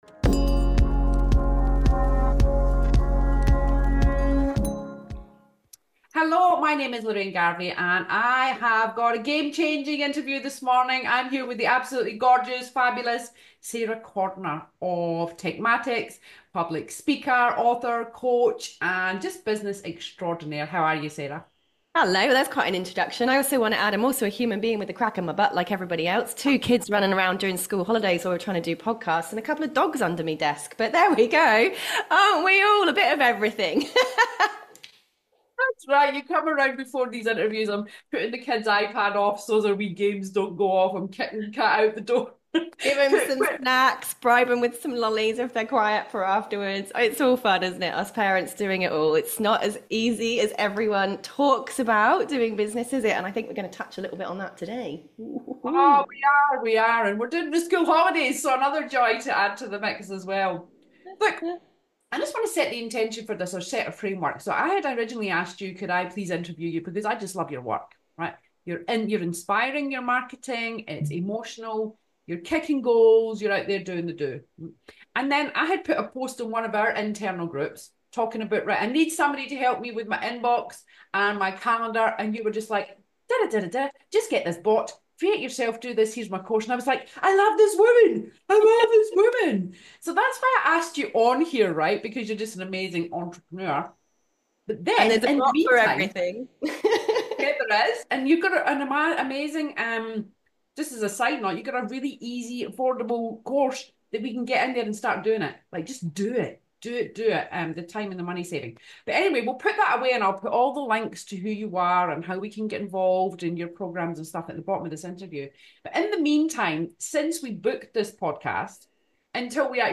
In this raw and powerful interview